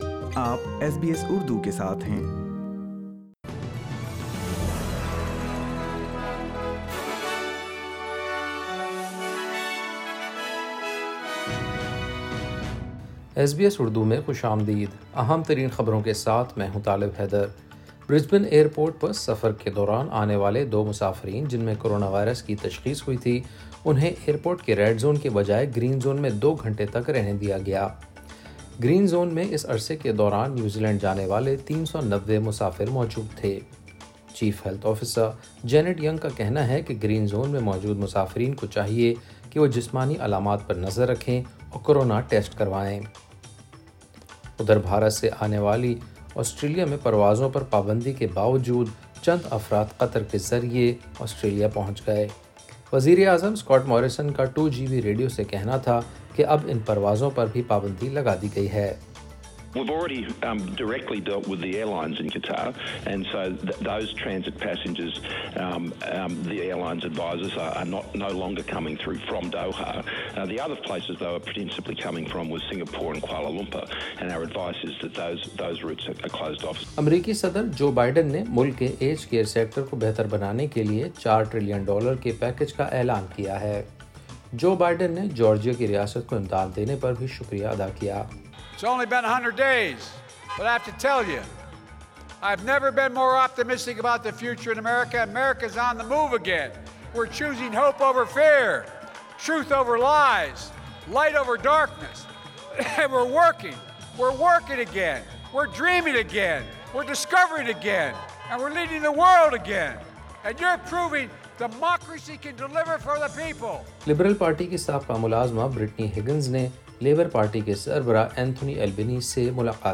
ایس بی ایس اردو خبریں 30 اپریل 2021